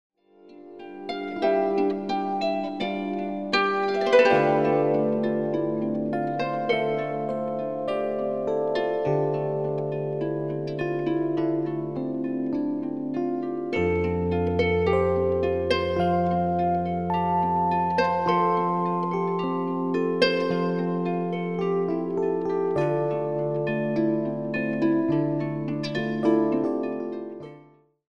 Featuring the harp, piano & guitar
Recorded at Healesville Sanctuary